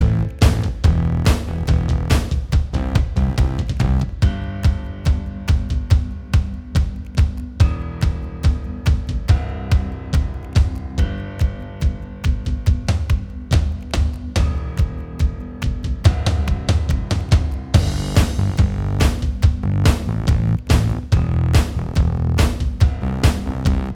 for solo male Pop (2010s) 3:37 Buy £1.50